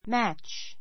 mǽtʃ